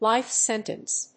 アクセントlífe séntence
音節lìfe séntence